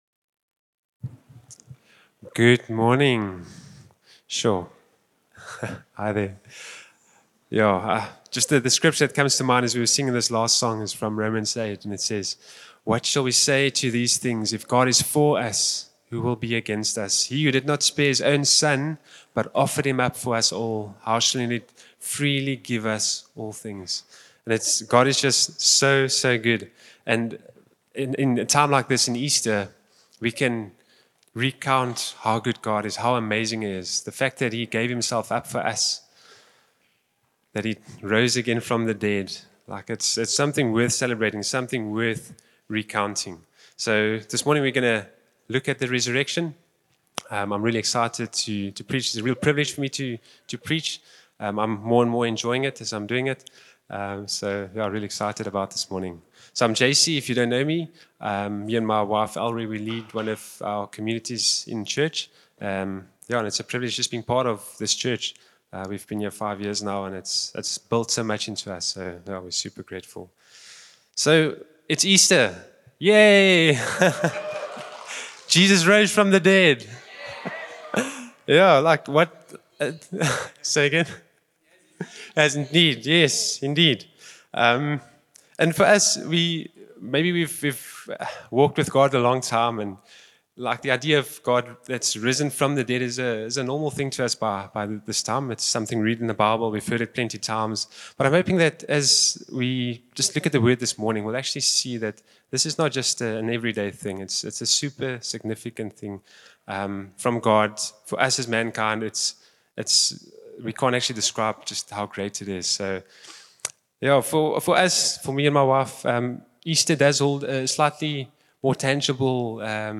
Listen to Sunday meeting messages from across our congregations in South Africa.